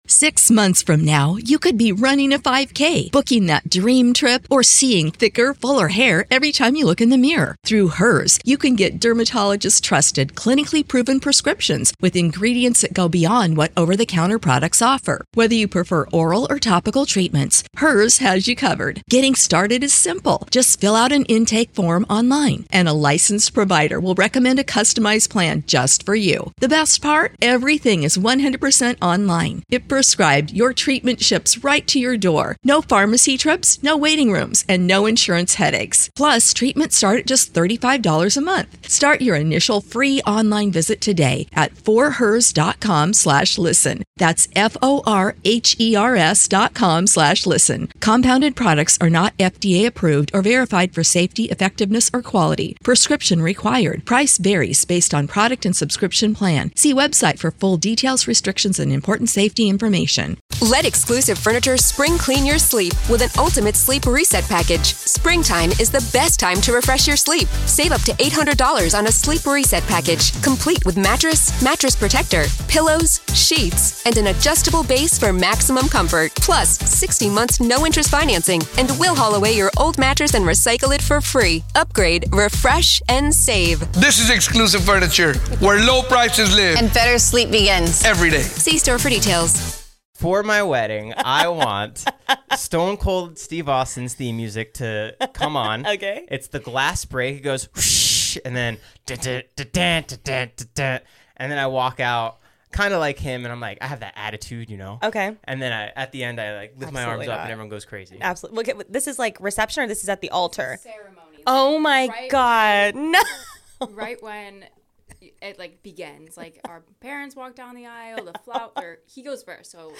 Society & Culture, Education, Hobbies, Music Commentary, Music Interviews, Relationships, Leisure, Health & Fitness, Self-improvement, Music, Fitness
My third solo interview with a guest for the Lightweights podcast!